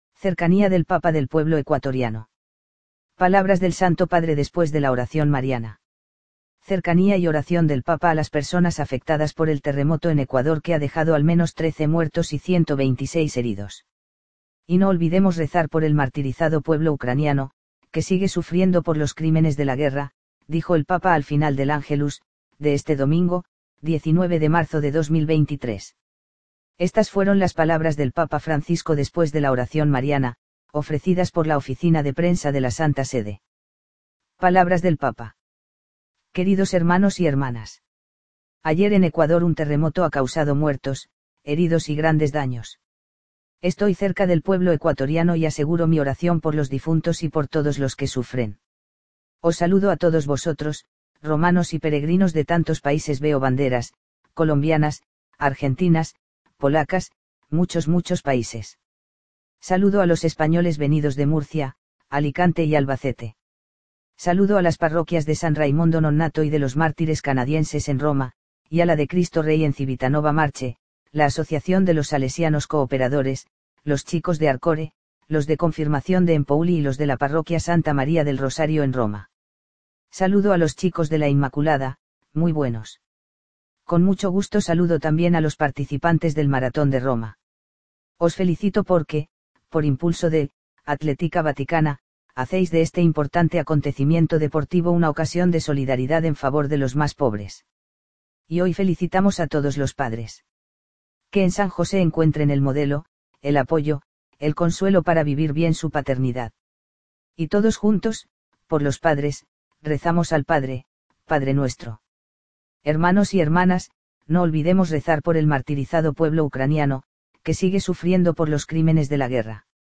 Palabras del Santo Padre después de la oración mariana